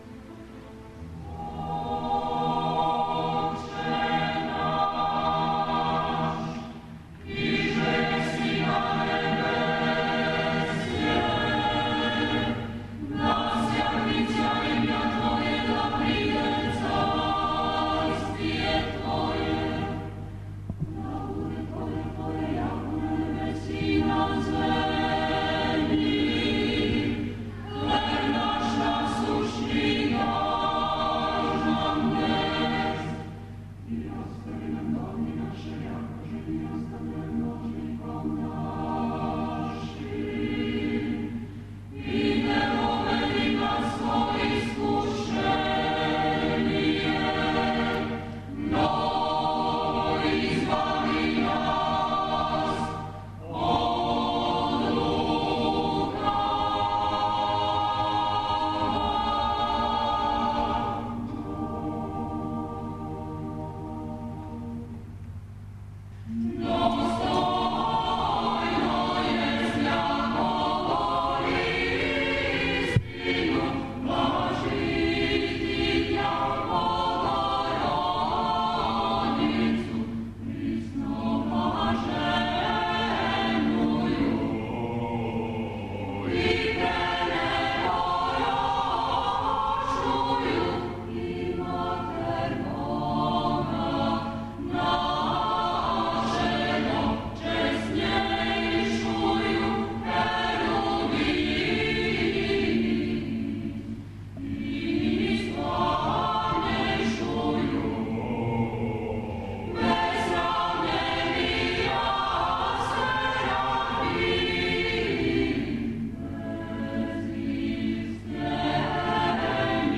под сводовима Богородичине цркве
хор Антифон извео је неколико композиција духовне и српске народне музике.
• Звучни запис концерта хора Антифон: